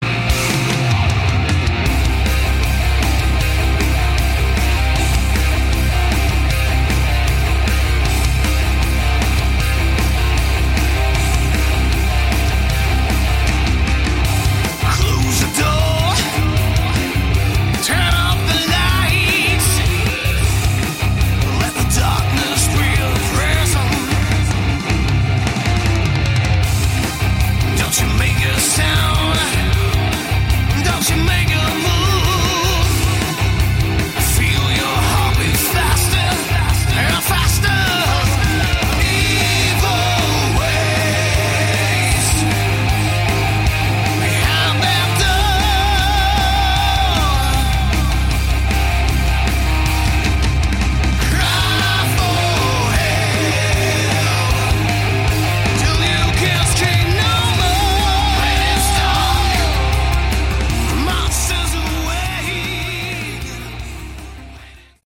Category: Melodic Metal
guitar, keyboards
vocals
drums